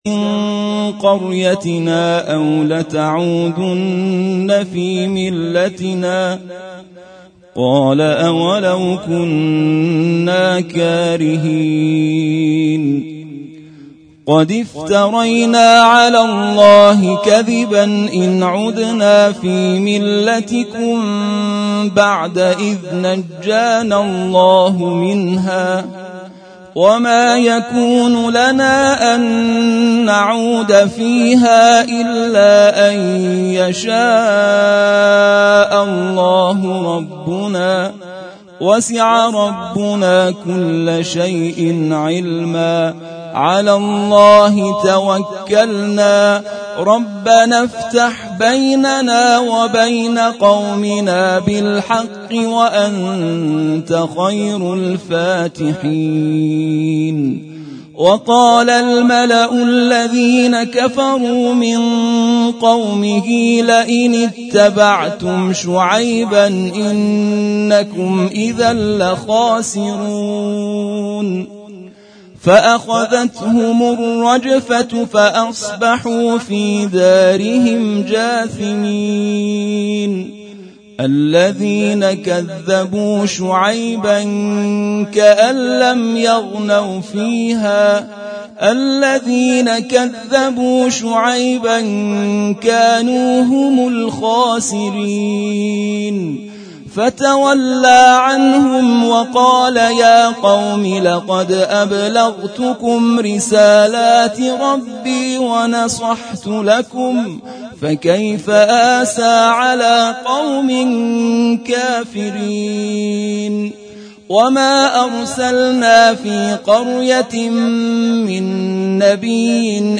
صوت هفتمین دوره جزءخوانی قرآن کریم